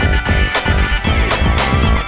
Happy 2 115-A.wav